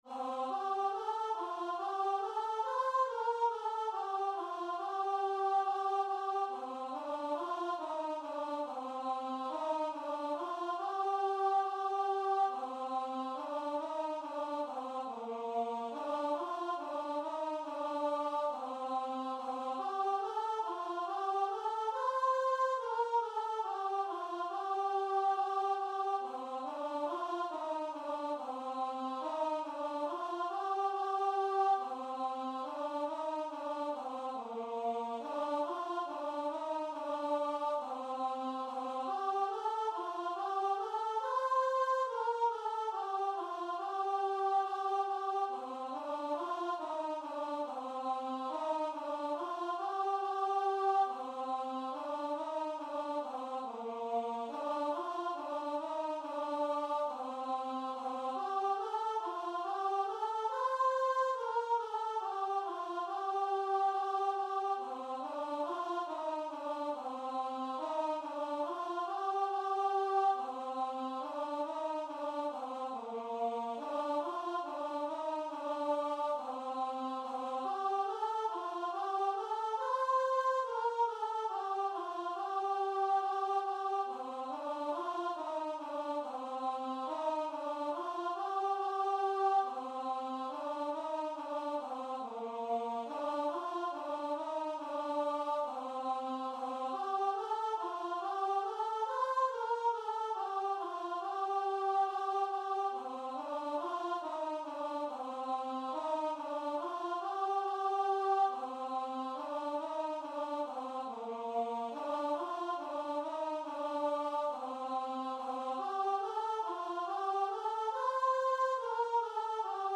4/4 (View more 4/4 Music)
Bb major (Sounding Pitch) (View more Bb major Music for Choir )
Choir  (View more Easy Choir Music)
Classical (View more Classical Choir Music)